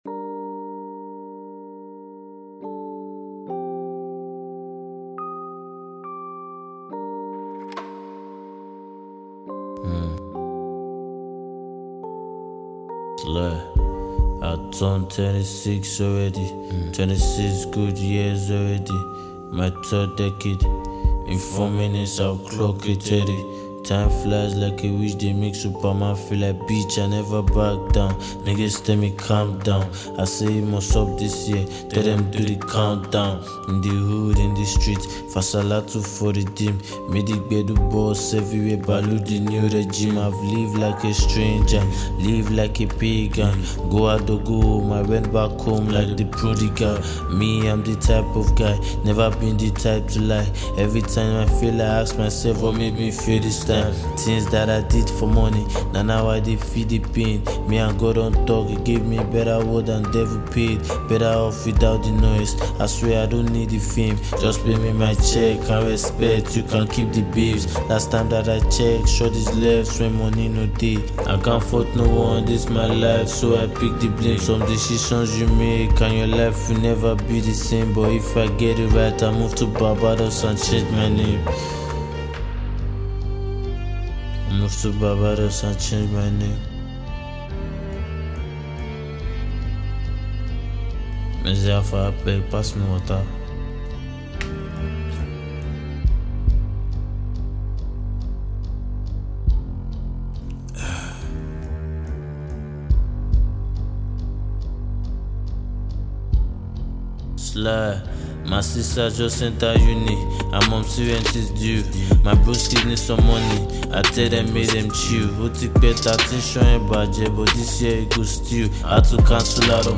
rap track